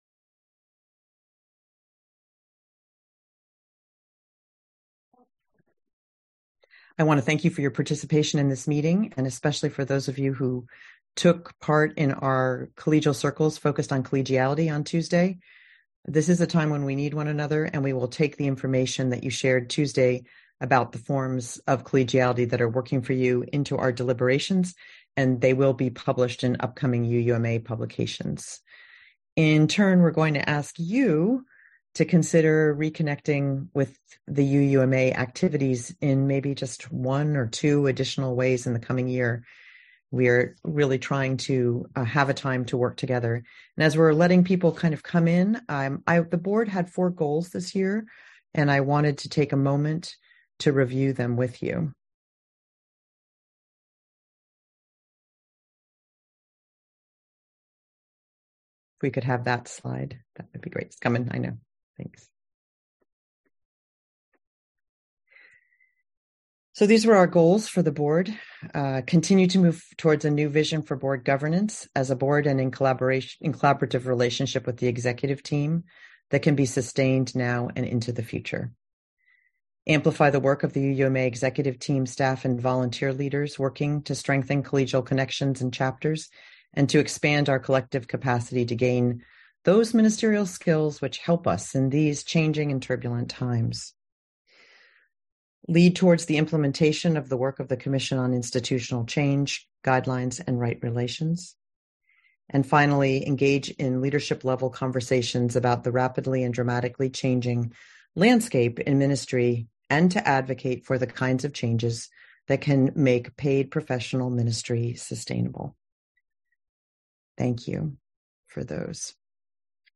June 16 Episode : Annual Meeting Part 2 This week we are presenting the second part of our Annual Meeting as our podcast episode